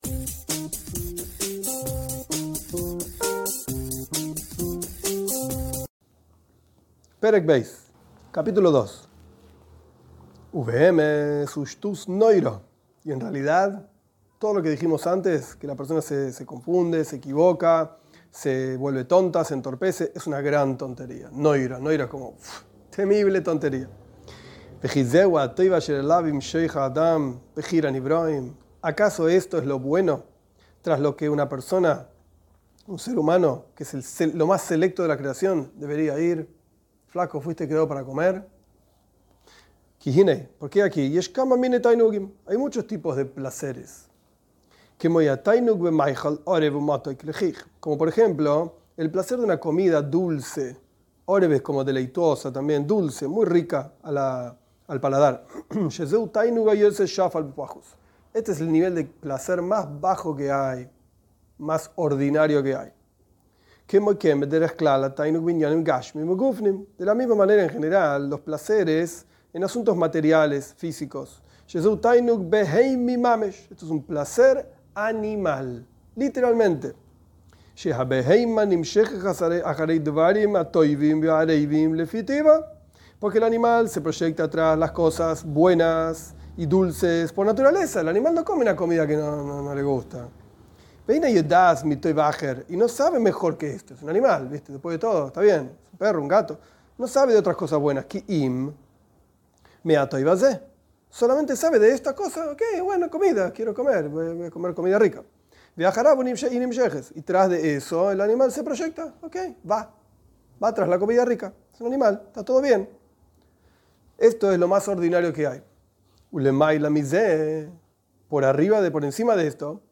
Esta es la primera clase de la serie "Superando impulsos". Se trata de la lectura y explicación del Kuntres uMaaian, del Rebe Rashab, sobre el refinamiento personal. Diferentes niveles de placer, el material, el de una voz y el de emociones.